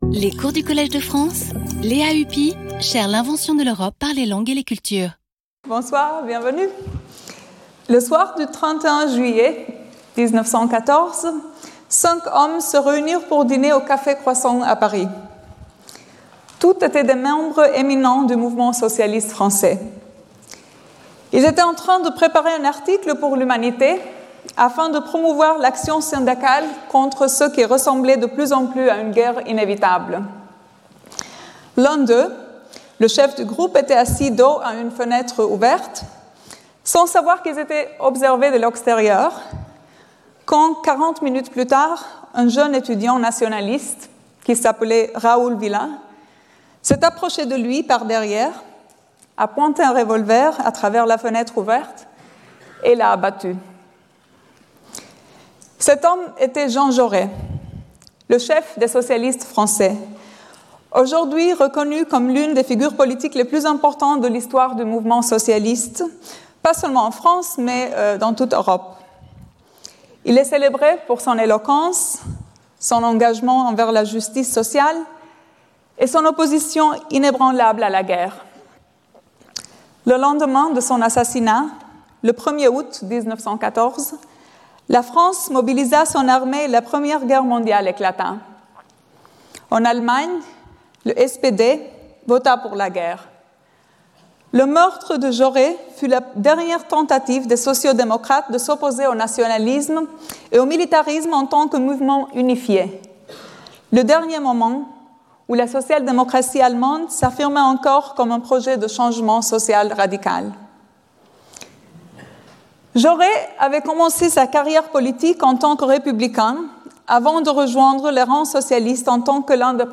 Speaker(s) Lea Ypi Professor of Political Theory at the London School of Economics and Political Science, Visiting Professor at the Collège de France
Lecture